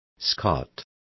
Complete with pronunciation of the translation of Scot.